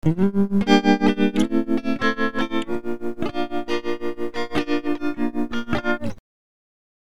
Tremolo with a constant frequency of 6 Hz, a minimum value of 0, and a maximum value of 1
clip23_7s_Tremolo_6hz.mp3